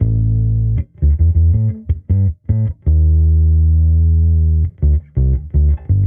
Index of /musicradar/sampled-funk-soul-samples/79bpm/Bass
SSF_PBassProc1_79A.wav